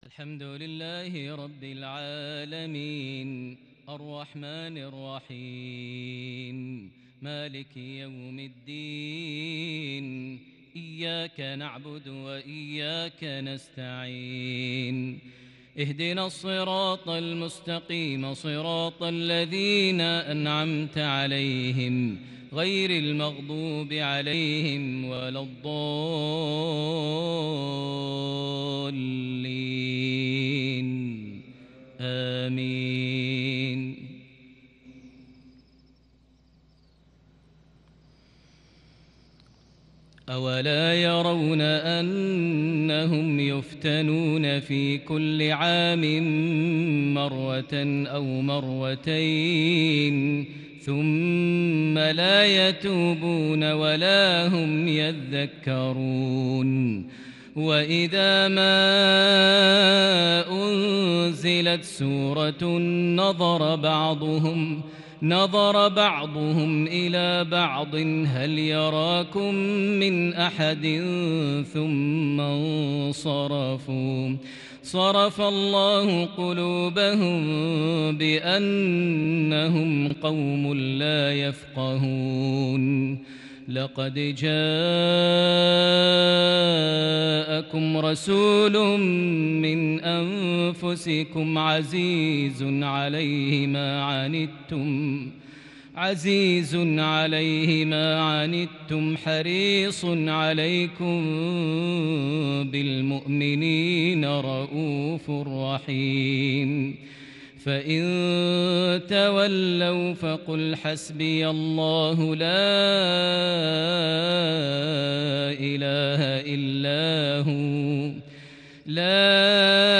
تنقلات كردية بديعة لخواتيم سورتي التوبة و الفتح | مغرب 21 صفر 1442هـ > 1442 هـ > الفروض - تلاوات ماهر المعيقلي